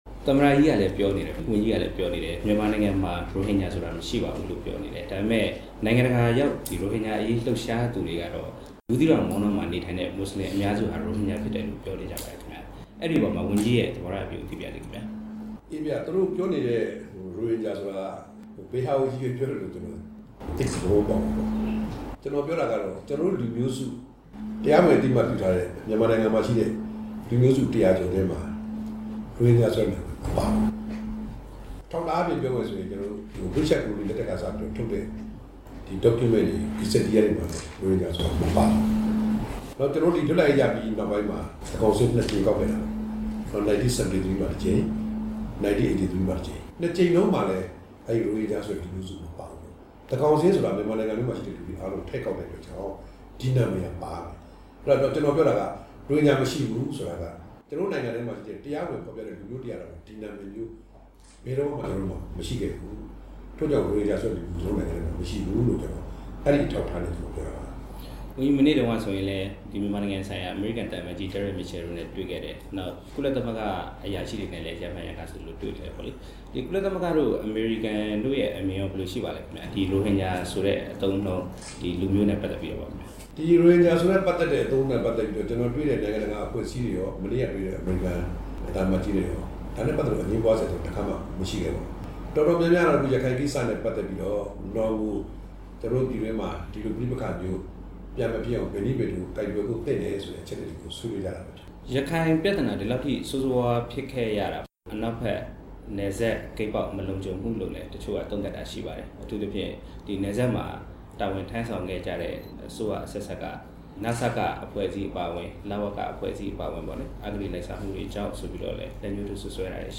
ပြည်ထောင်စုဝန်ကြီး ဦးခင်ရီနှင့် ရိုဟင်ဂျာအရေး တွေ့ဆုံမေးမြန်းချက်